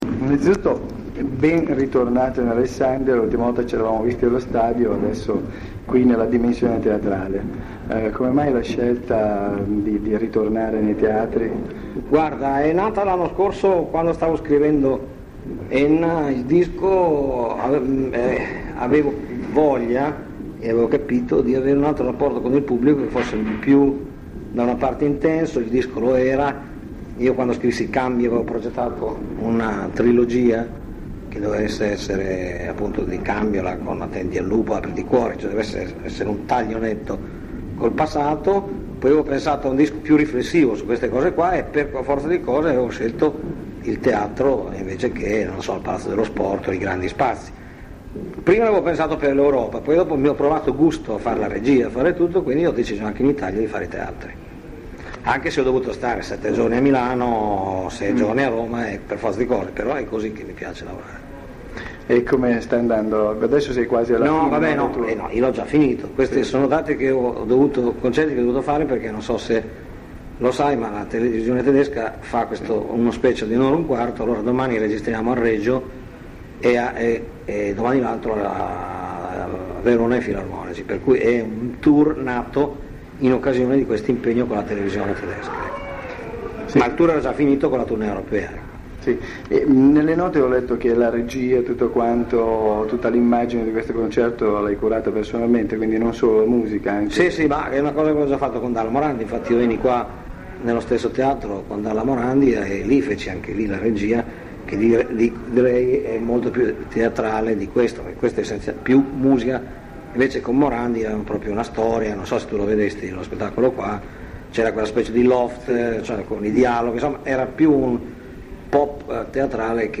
L'intervista del 4 dicembre 1994